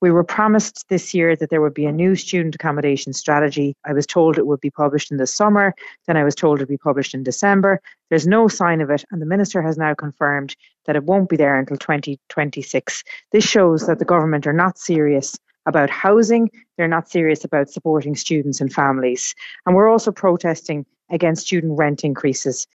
Speaking in advance of today’s action, Labour’s spokesperson on further and higher education Senator Laura Harmon says the cost of housing and education is crucifying families, and pushing people out of education……………